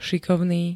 Zvukové nahrávky niektorých slov
m6po-sikovny.ogg